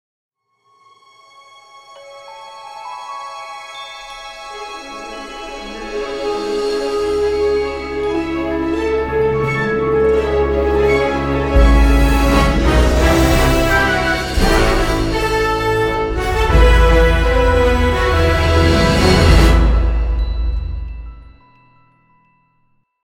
ce jingle orchestral